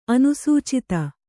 ♪ anusūcita